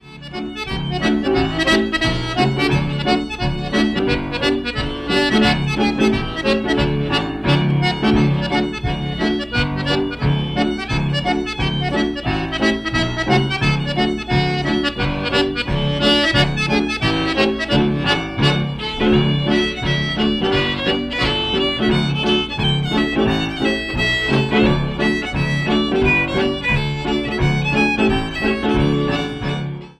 The military schottische, a favorite among trailerites, is a lively group dance seldom seen outside of trailer park circles.